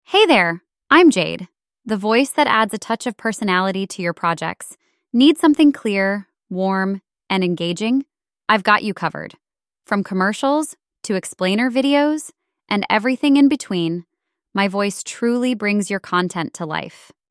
Custom voiceovers to add a personal touch